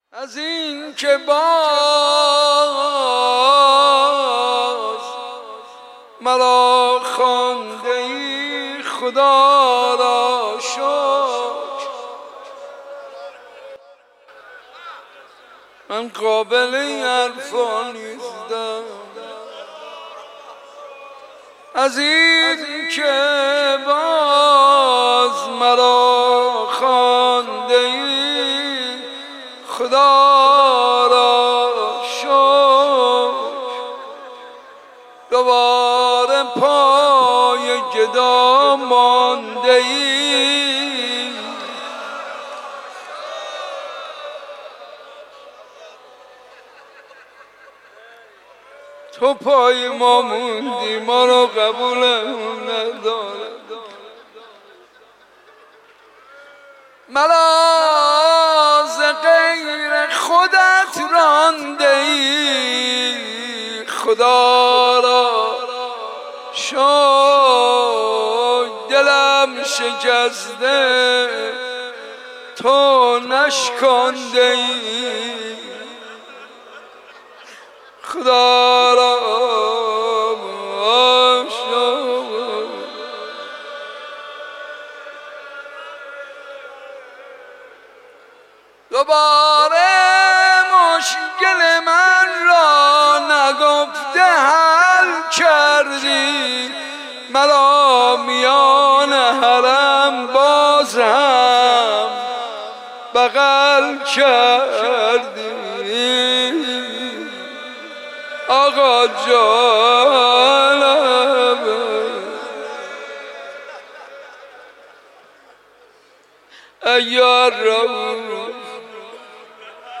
مداحی به سبک مناجات اجرا شده است.